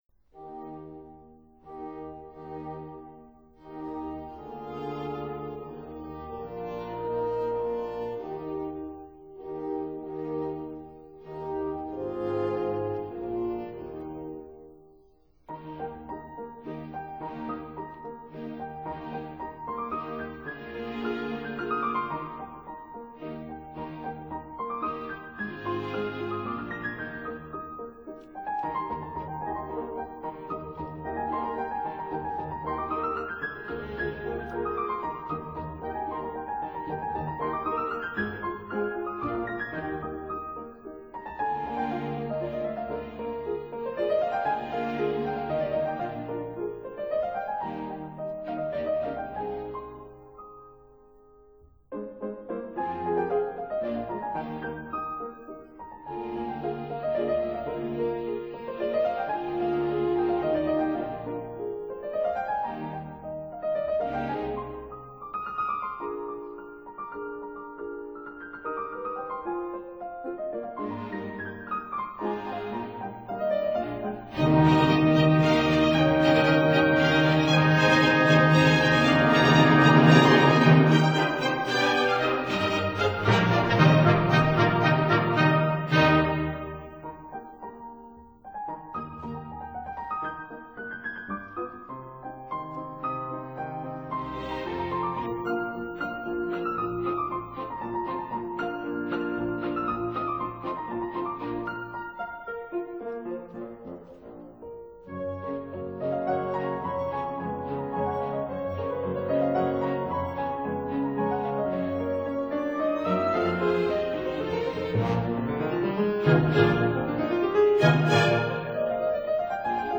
Piano Concerto No. 2 in A flat